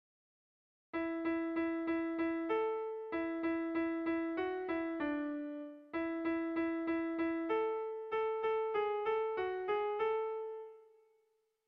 Air de bertsos - Voir fiche   Pour savoir plus sur cette section
Irrizkoa
Bakaiku < Sakana < Iruñeko Merindadea < Nafarroa < Euskal Herria
AB